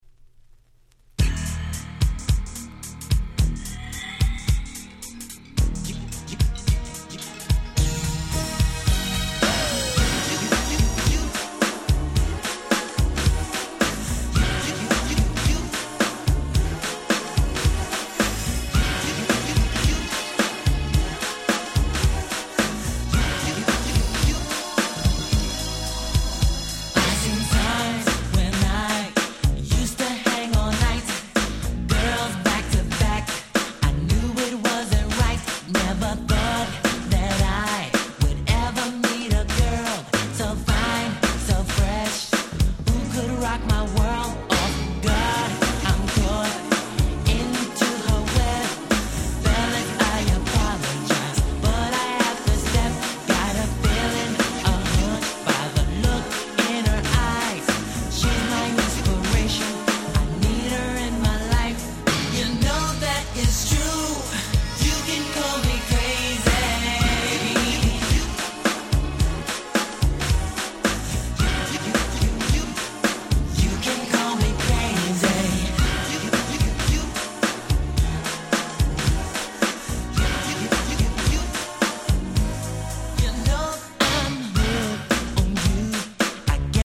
【Media】Vinyl LP
80's NJS ニュージャックスウィング  ハネ系